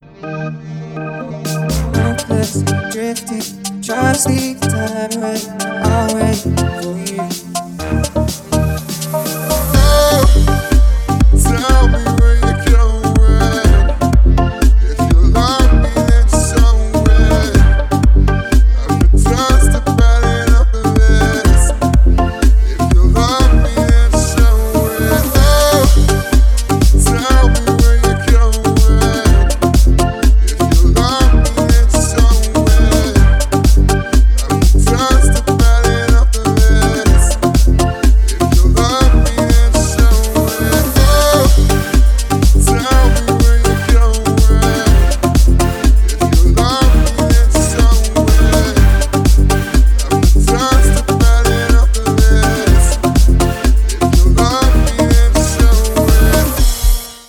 • Качество: 320, Stereo
мужской вокал
dance
club